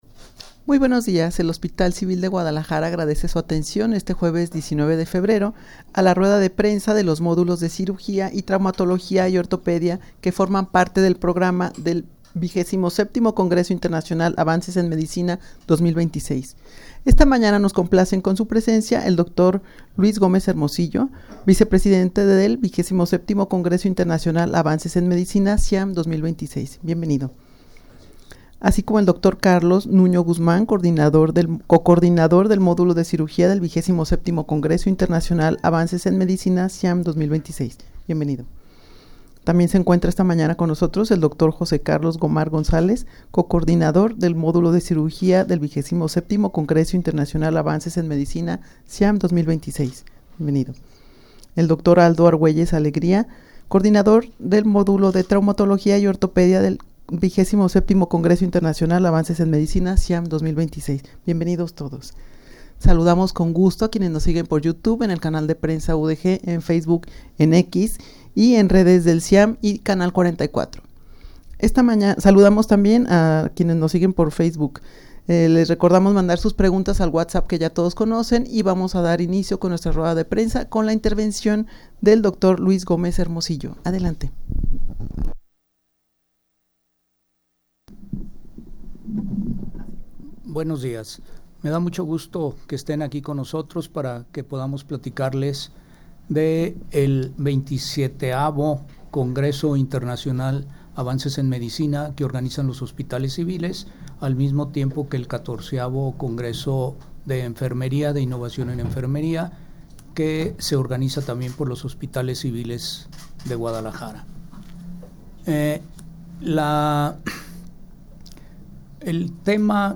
Audio de la Rueda Prensa